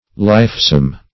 Search Result for " lifesome" : The Collaborative International Dictionary of English v.0.48: Lifesome \Life"some\ (l[imac]f"s[u^]m), a. Animated; sprightly.